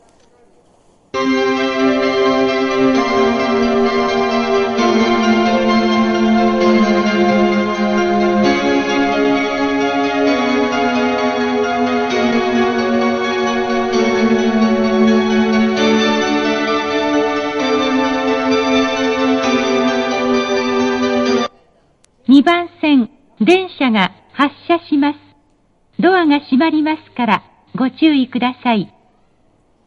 発車メロディー
接近放送 「Verde Rayo V2」です。
●スピーカー：National天井丸型
●音質：良